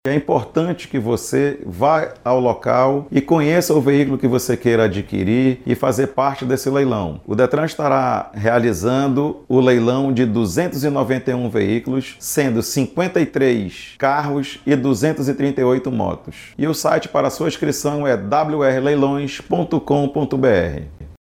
SONORA-1-DETRAN.mp3